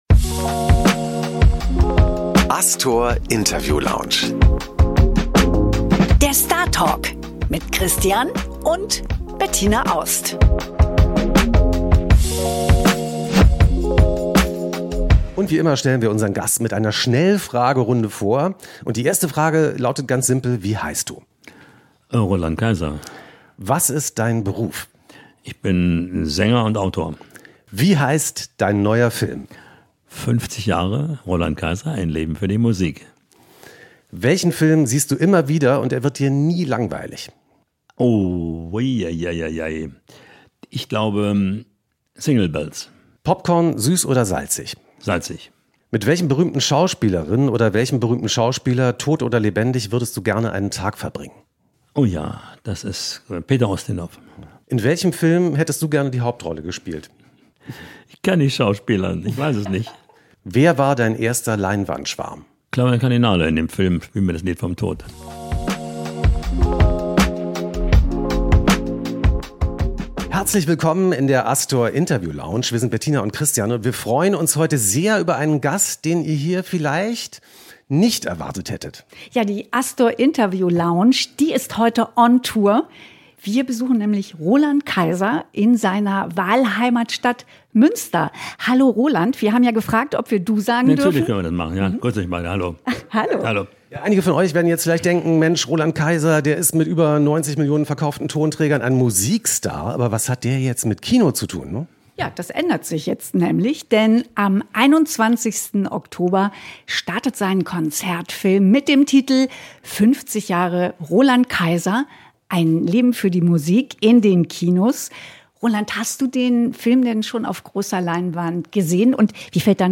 Diesmal zu Gast in der Interview Lounge: Roland Kaiser!